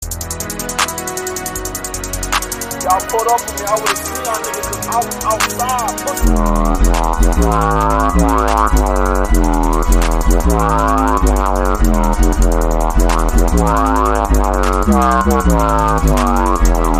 running off Meme Sound Effect